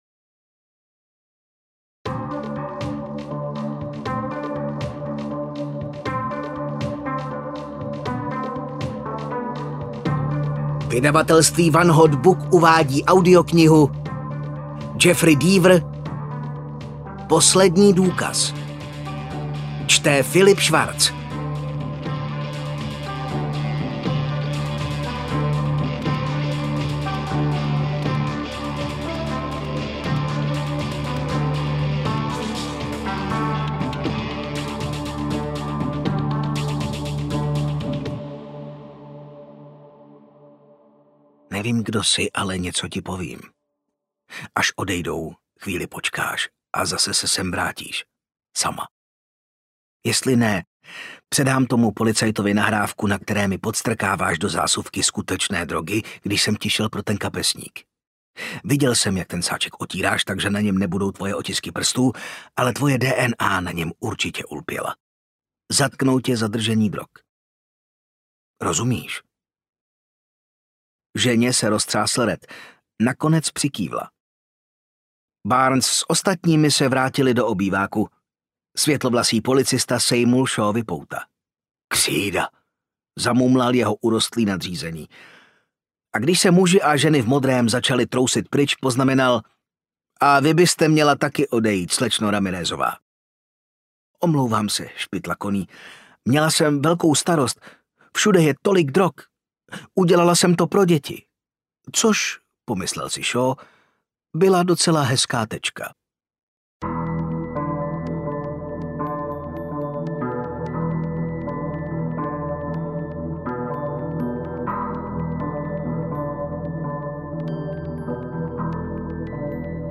Poslední důkaz audiokniha
Ukázka z knihy